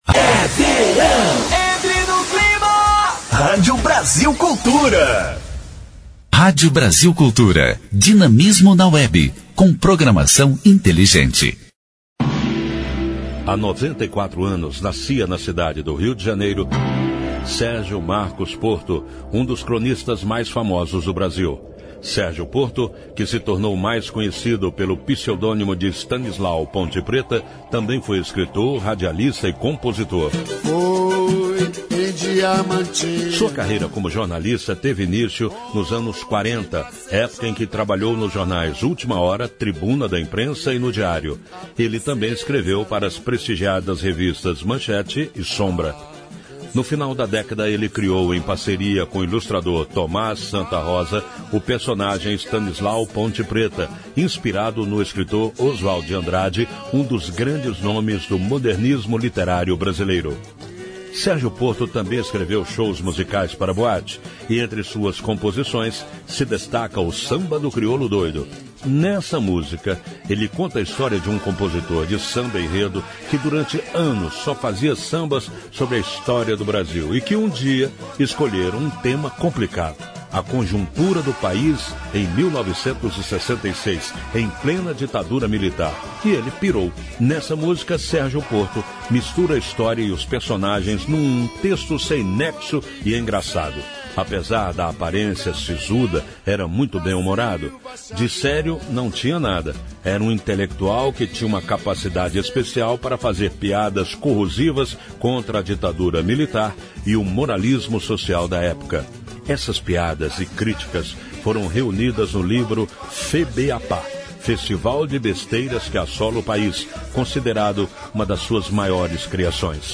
História Hoje: Programete sobre fatos históricos relacionados às datas do calendário. Vai ao ar pela Rádio Brasil Cultura de segunda a sexta-feira.